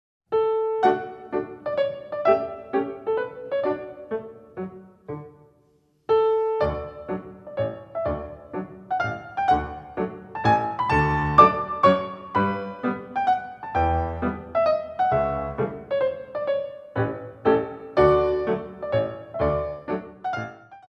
Echappes A La Barre